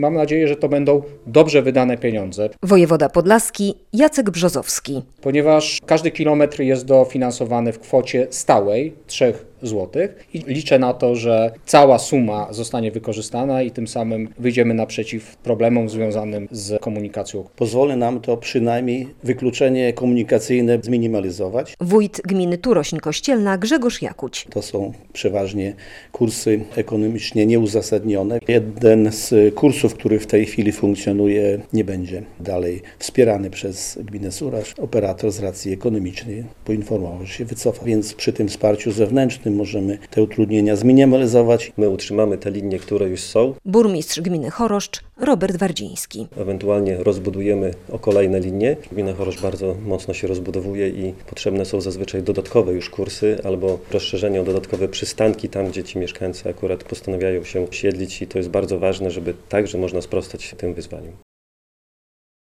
Nabór wniosków na dofinansowanie na przewozy autobusowe - relacja